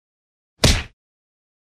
Звуки ударов руками, ногами
Звук удара по щеке